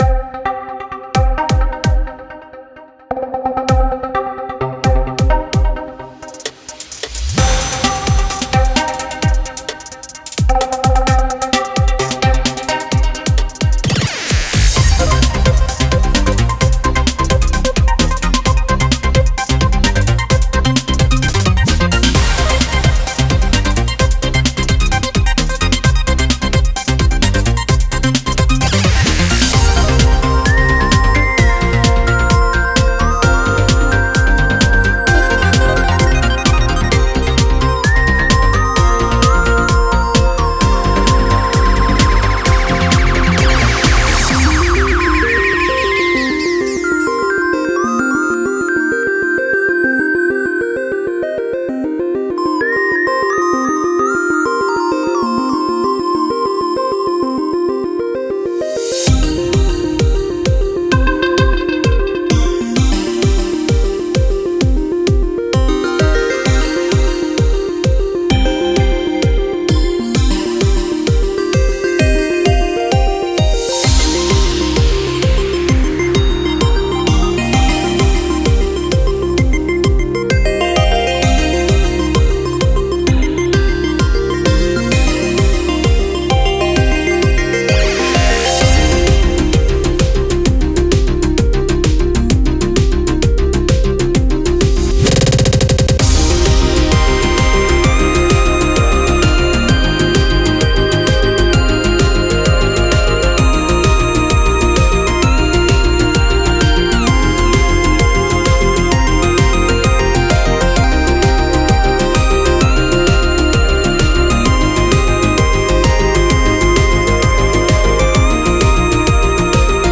the sound choice is great. The lushness and the depth really draw in my interest here, there's a good mixture of driving sounds with enough of the old chiptune flair here